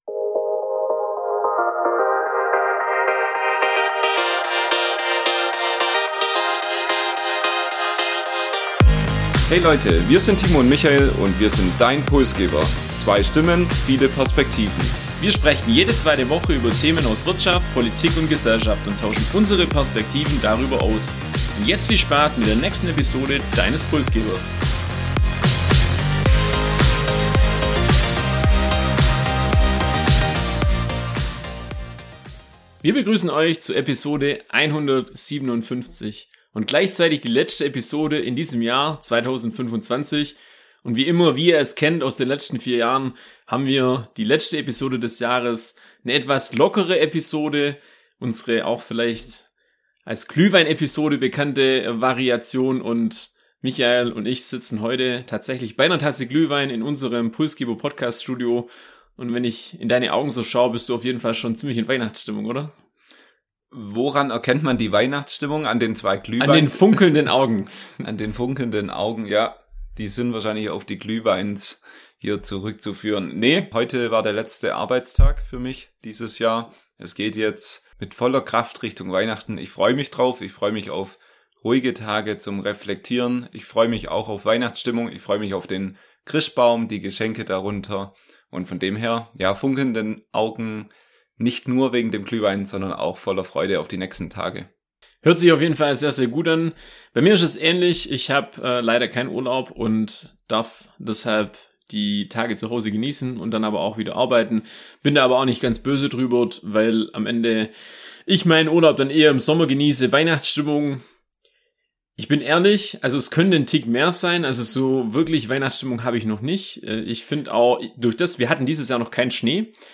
Eine persönliche, ehrliche und entspannte Jahresabschlussfolge mit Reflexion, Ausblick und einem großen Dank an euch, unsere Hörerinnen und Hörer.